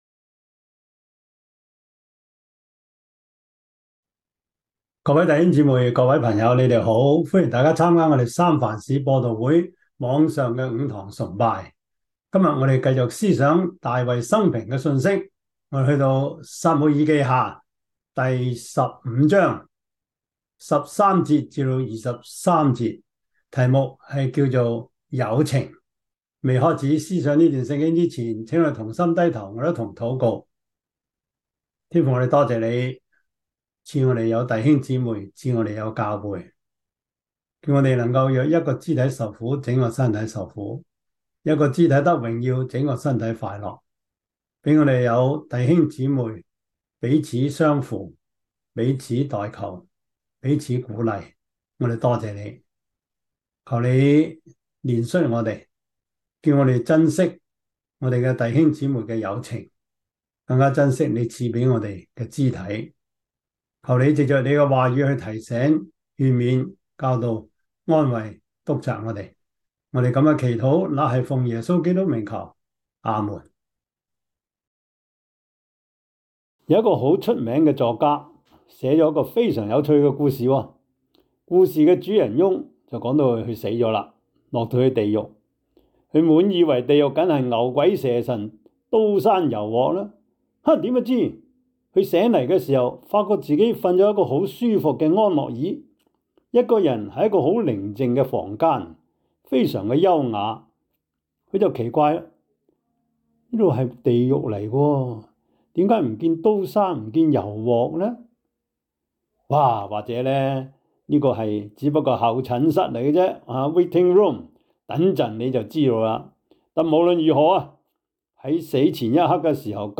撒母耳記下 15:13-23 Service Type: 主日崇拜 撒母耳記下 15:13-23 Chinese Union Version
Topics: 主日證道 « 新天新地 秋收冬藏 »